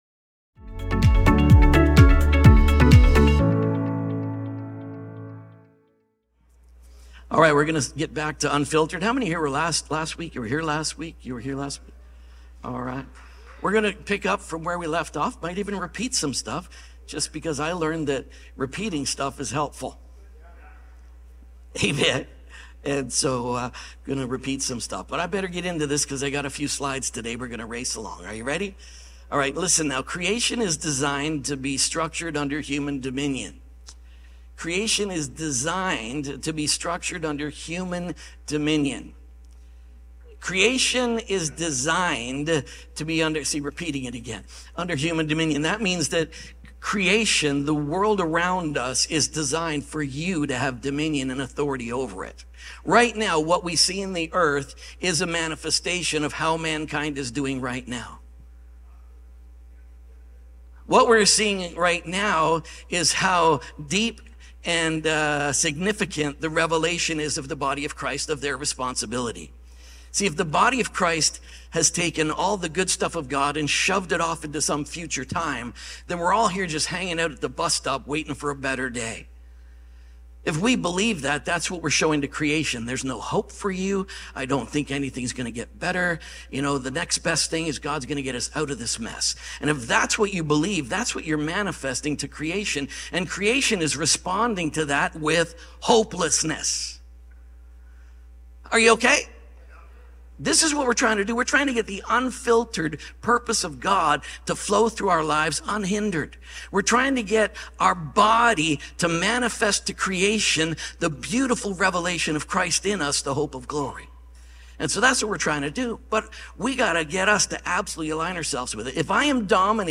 Expressed Union | UNFILTERED | SERMON ONLY.mp3